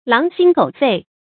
狼心狗肺 注音： ㄌㄤˊ ㄒㄧㄣ ㄍㄡˇ ㄈㄟˋ 讀音讀法： 意思解釋： 比喻心腸如狼和狗 一樣兇惡、狠毒。